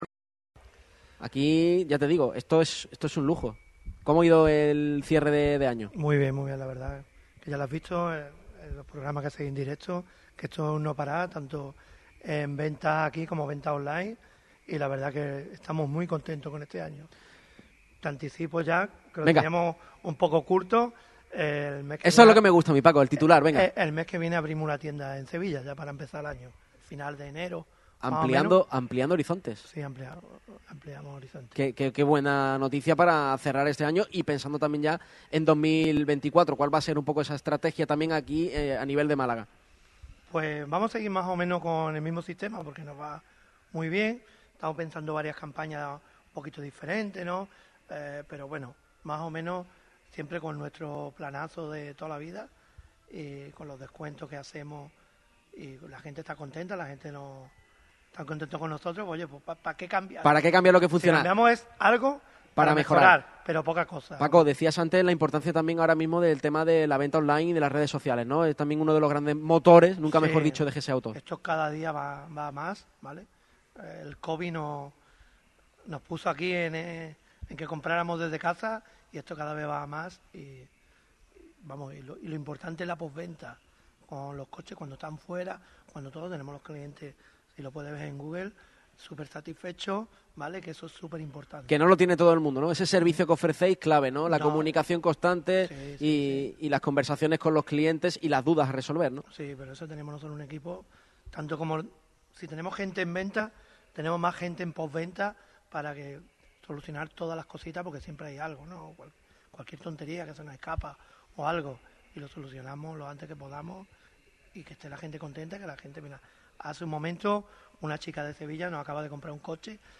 En el último programa del año, GS Auto abre sus puertas y recibe con los brazos abiertos a los compañeros de Radio Marca Málaga. Este martes el equipo de Radio MARCA Málaga ha visitado las instalaciones de GS Autos en el Polígono de Santa Bárbara C/ Hnos Lumiere 17.
Escuchamos las palabras de Ibon Navarro después del partido.